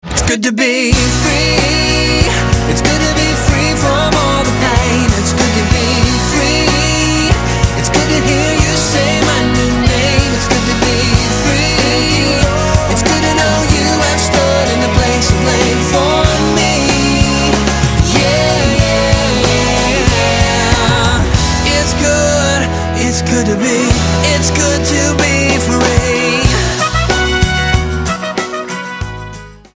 STYLE: Pop
acoustic-driven pop and rock